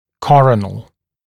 [‘kɔrənl][‘корэнл]коронковый (относящийся к коронке зуба); венечный, коронарный